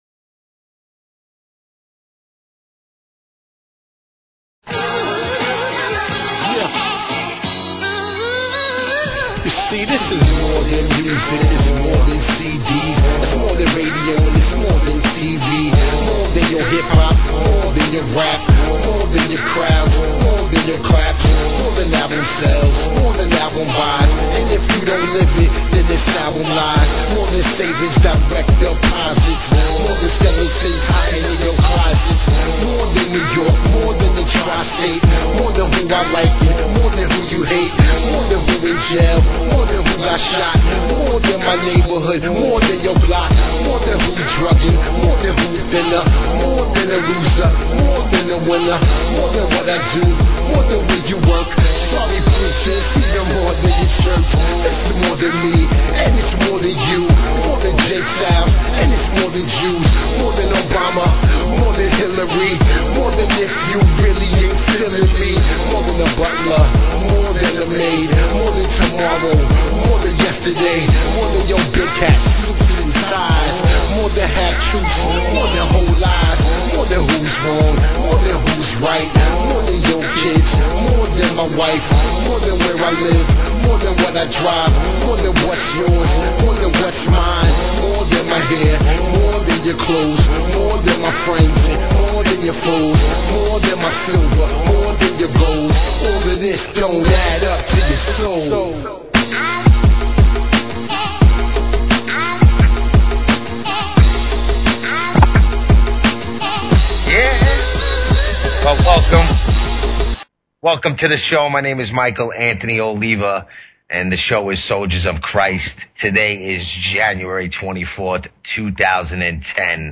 Talk Show Episode, Audio Podcast, Soldiers_of_Christ and Courtesy of BBS Radio on , show guests , about , categorized as
PREACHIN THE WORD WITH CHRISTIAN HIP HOP AND SOUL!
Spreading the good word; plus hip hop and soul inspired by the Gospel!